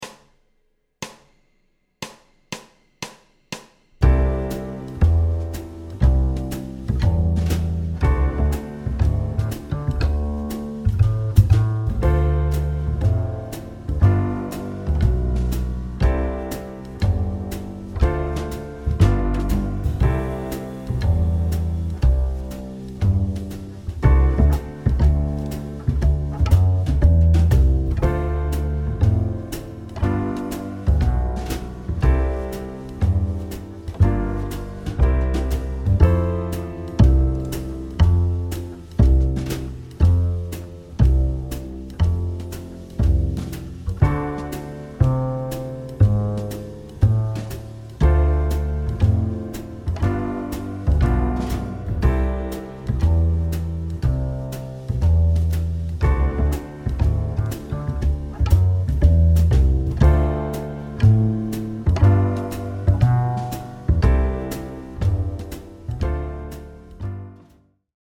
• un rythme entrainant et une mélodie accrocheuse
Piste d’accompagnement